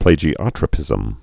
(plājē-ŏtrə-pĭzəm)